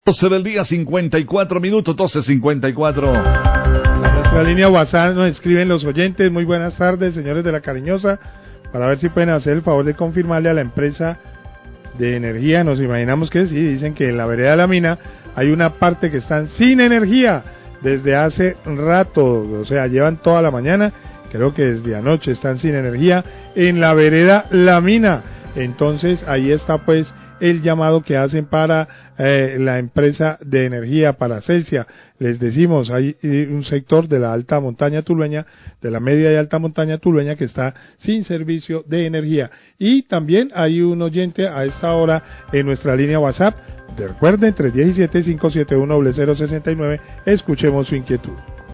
Mensaje a través del whatsapp de la emisora donde reportan que en la vereda La Mina del municipio de Tuluá se presentó un corte de energía desde ayer y aún no se restablece el servicio. Hacen un llamado a la empresa de energía Celsia.